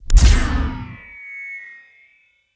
SCIEnrg_Shield Activate_02_SFRMS_SCIWPNS.wav